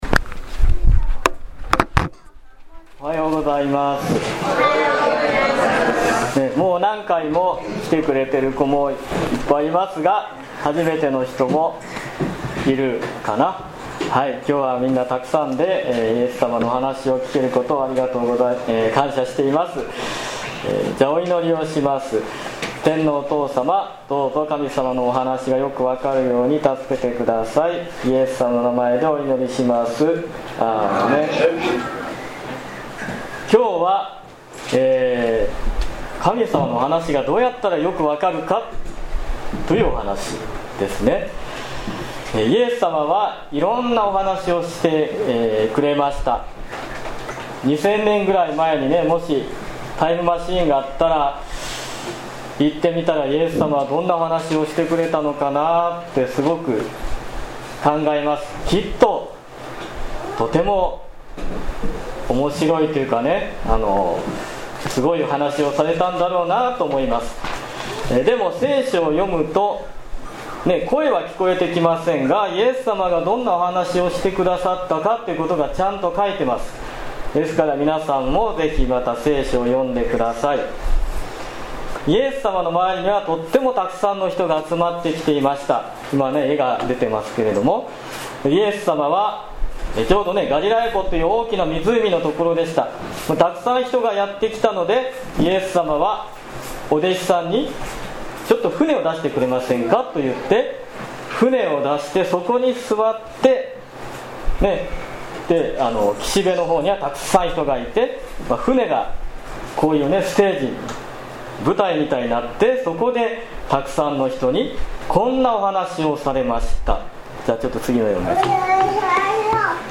2018年7月15日礼拝メッセージ